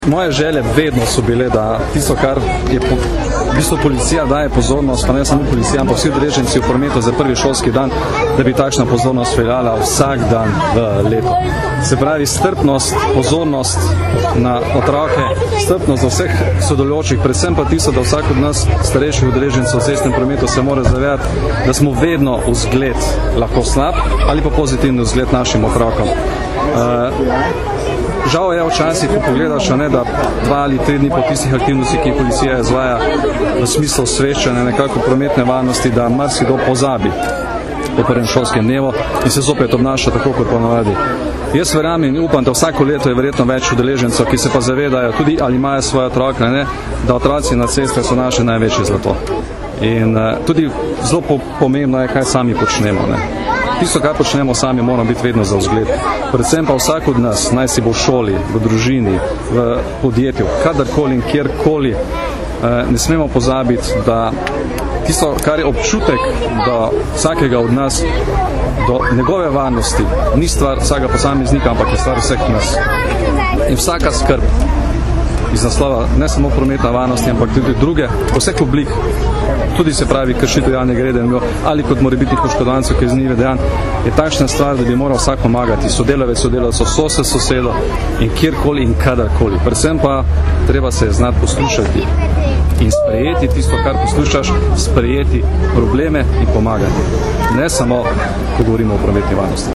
Zvočni posnetek izjave generalnega direktorja policije Janka Gorška (mp3)